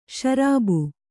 ♪ śarābu